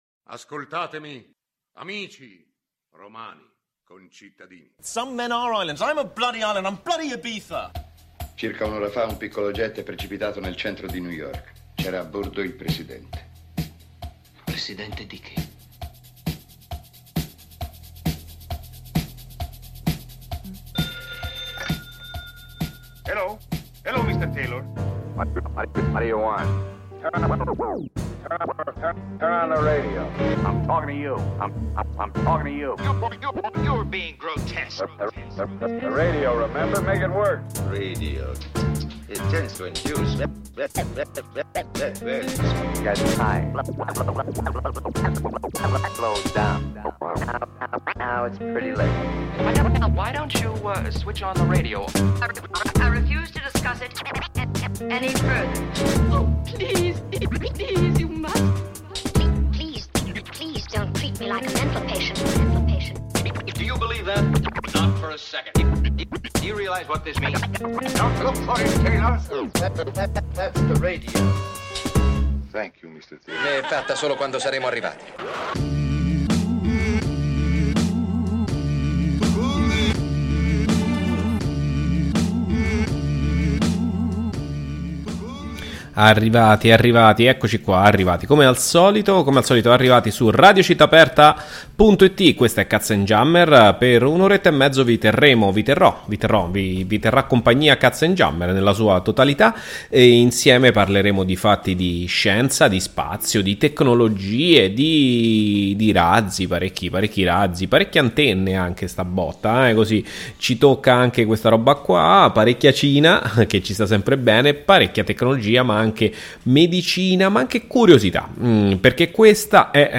Il tutto condito con musica a tema e meno a tema, nuova e meno nuova e con una strizzatina d’occhio agli anni 90, che lo sappaimo che vi piace…